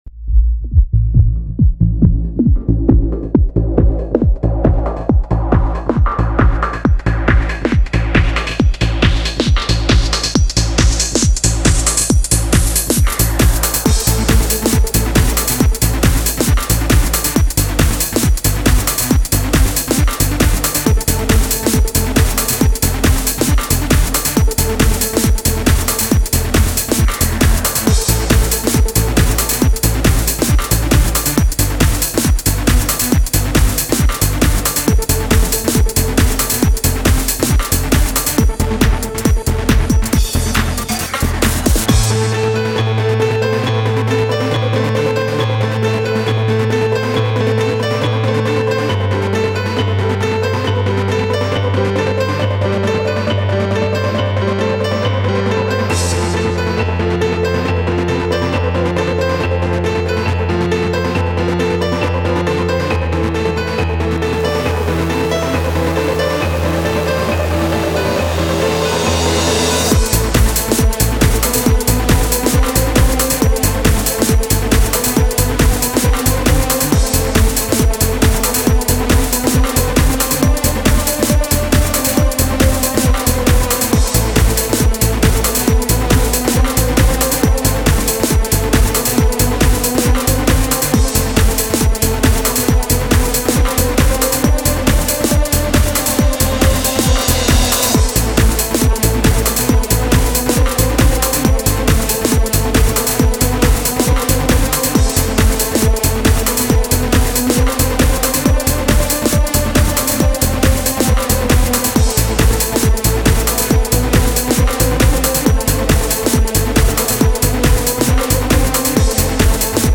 Написал трек в стиле Trance...
середина несколько затянута; после правда следует мелодичное развитие, но кончается оно слишком быстро, из-за чего концовка кажется "пустоватой"
Кстати пиано и некоторые электронные лиды записывал вручную с синтезатора Yamaha psr550!